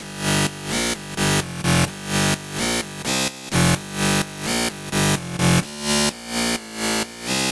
电子低音1
描述：嘎吱嘎吱的房子电子低音序列